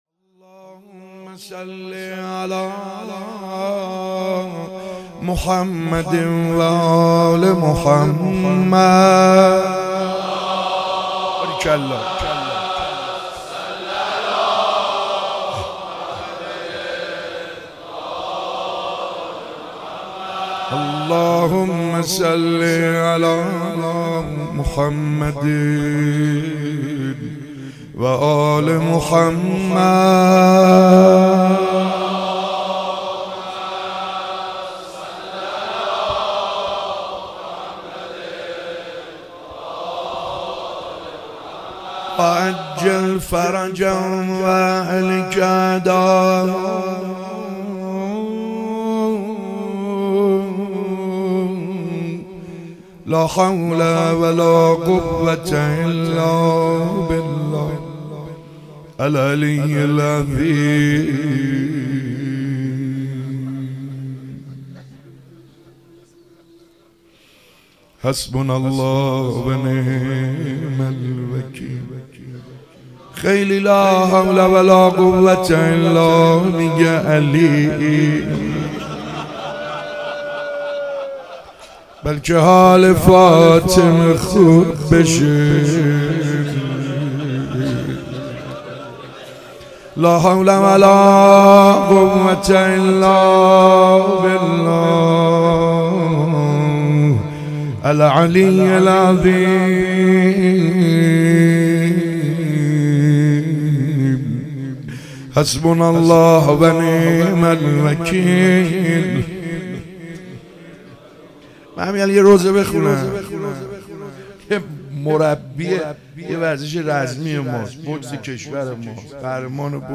شب 4 فاطمیه 95 - دعا خوانی